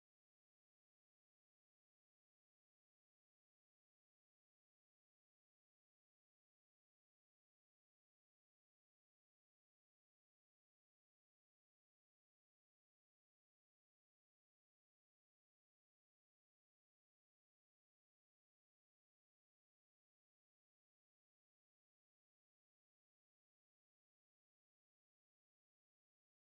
וואו וואו....תקיפת מתקן הגרעין בנתנז איזה עוצמה של פיצוצים.